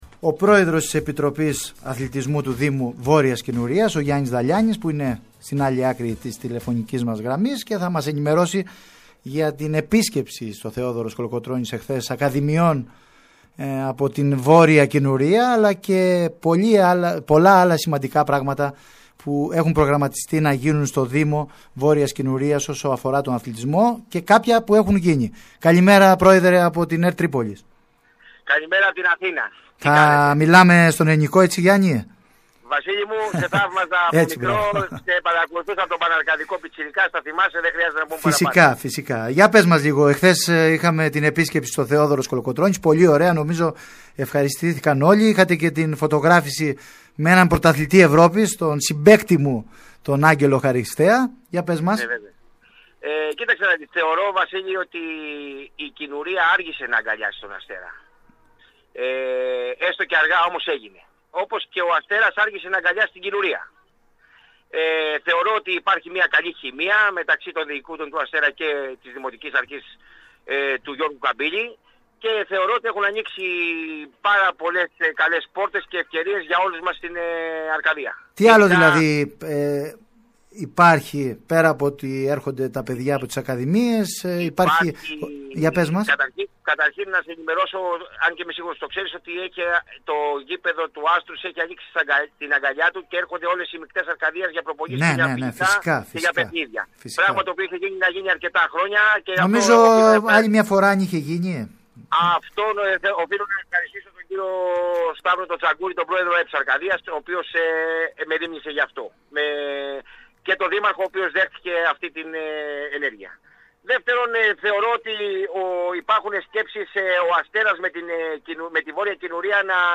μίλησε στην ΕΡΤ Τρίπολης